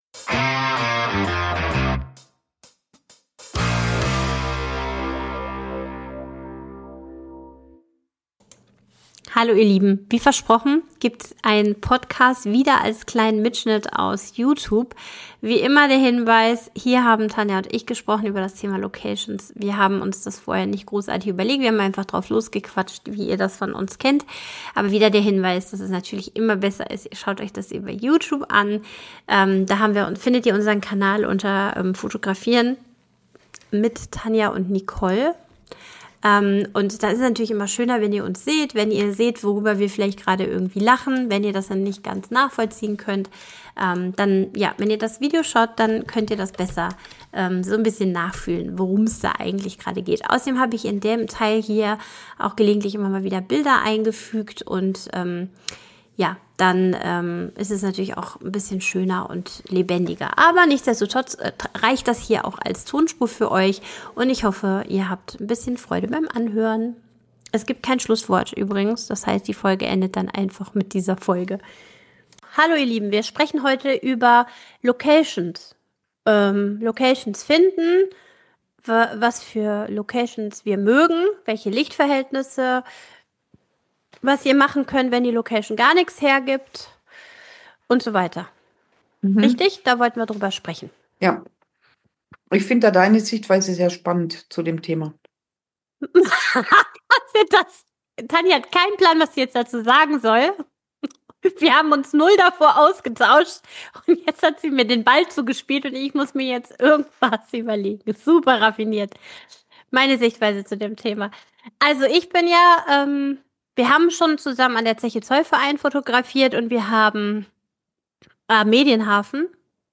Das ist wieder ein YouTube Video, dass wir für Euch umgemodelt haben, aber eine Plauderei ist eine Plauderei :-)